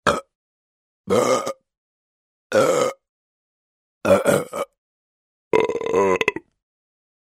человек рыгает звук